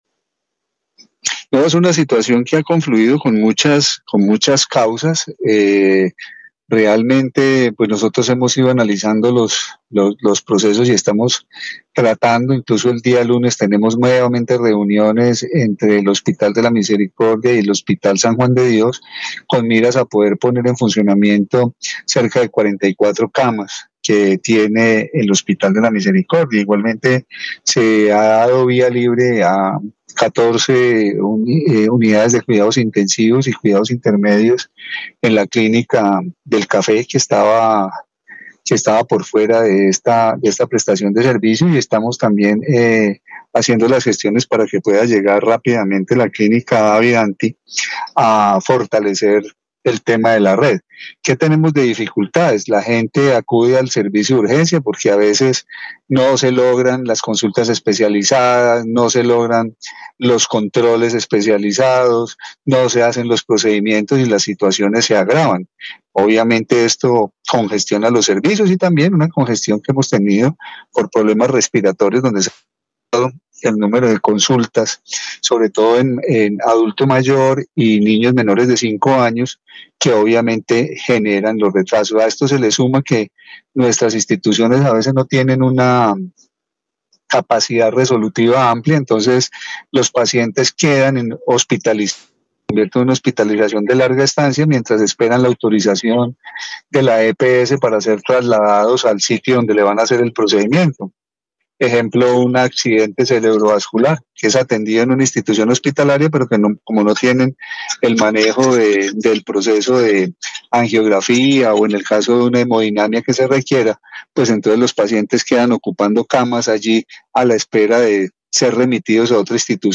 Carlos Alberto Gómez, secretario de salud del Quindío
En Caracol Radio Armenia hablamos con el secretario de salud del departamento del Quindío, Carlos Alberto Gómez que manifestó su preocupación por la alta ocupación de los servicios de urgencias en UCI tanto en los hospitales como en las clínicas privadas.